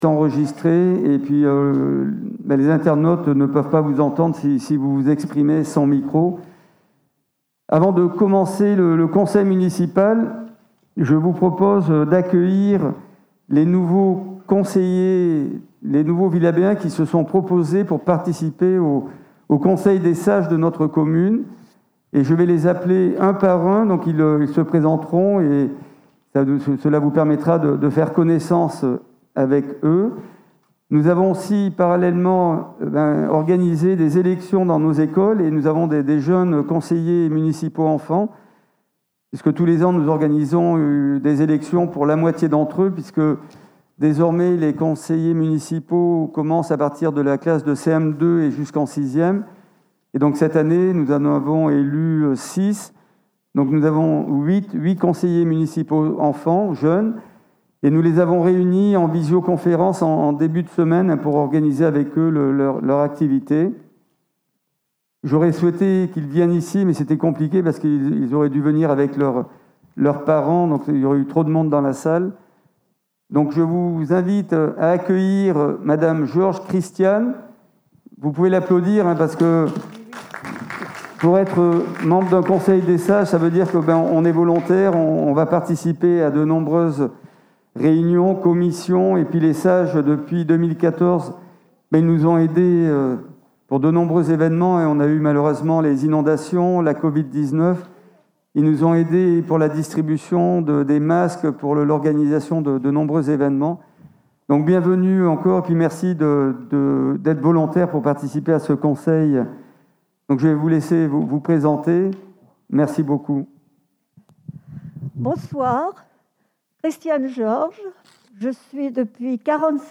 Comptes rendus des séances du conseil municipal